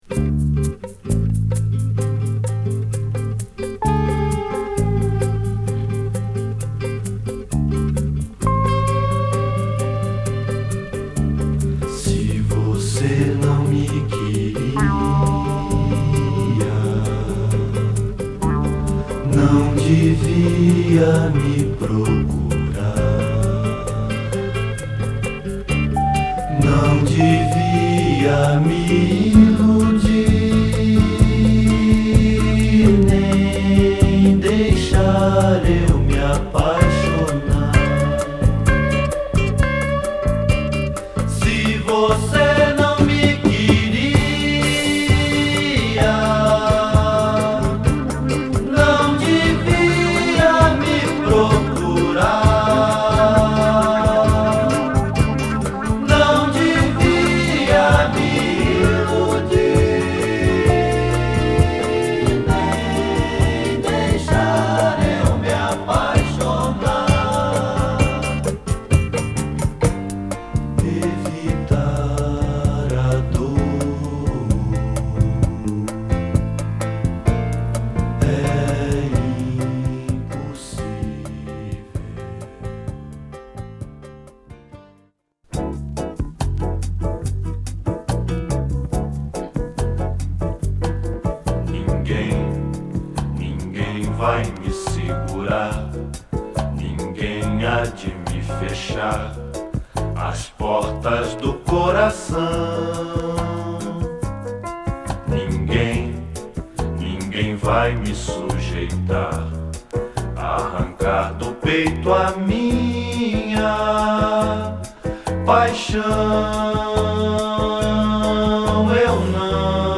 ボサボヴァ〜サンバ〜MPBと確かなヴォーカルワークでクオリティ高いカヴァー集に仕上がってます！